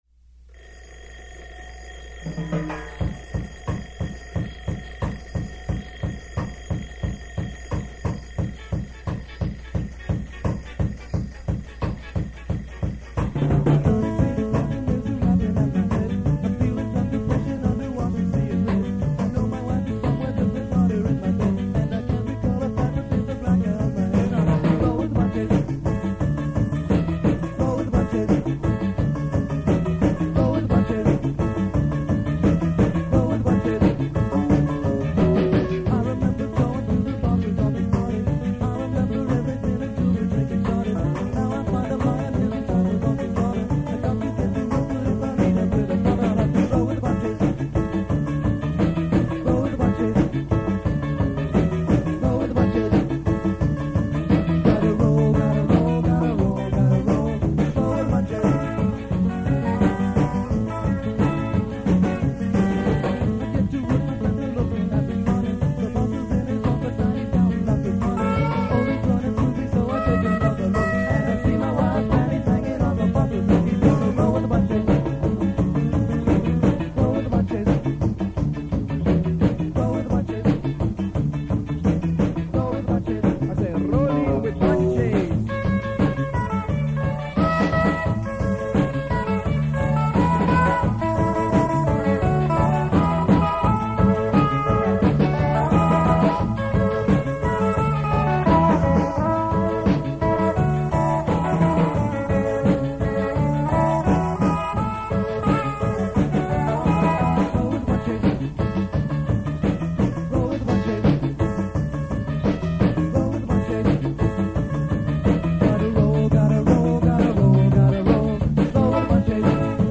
Keep in mind that these are from very old cassette tapes, so sound quailty is definitely an issue.
The band played some covers from The English Beat, Madness and The Specials, but mostly played original material in the style of ska and reggae.
written by Silent Q: from the Rehearsal tape